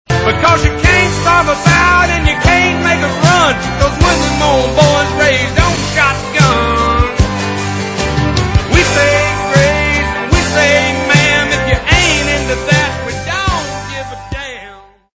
Tags: ringtones tones cell phone music melody country songs